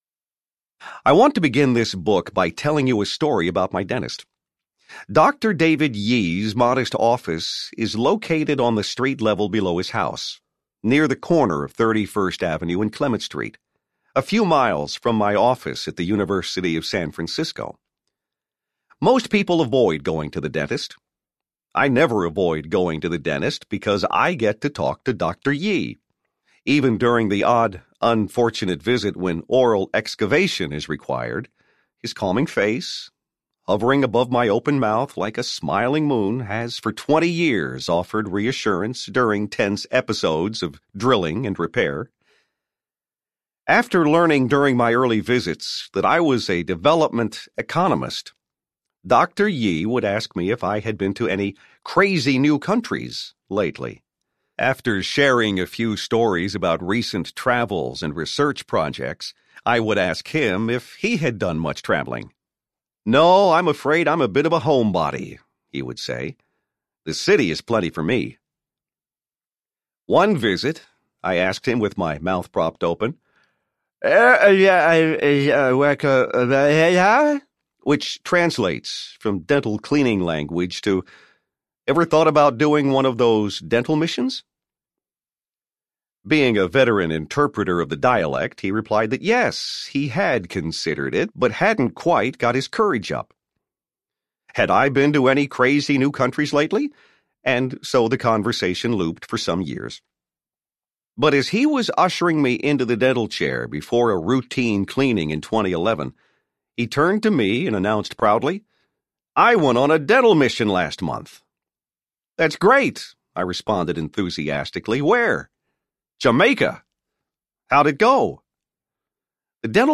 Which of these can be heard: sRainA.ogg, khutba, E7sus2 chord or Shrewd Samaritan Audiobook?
Shrewd Samaritan Audiobook